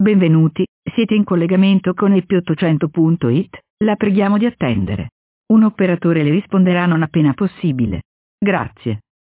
Esempi di messaggi audio sintetizzati:
Messaggio di Benvenuto:
Messaggio-benvenuto.mp3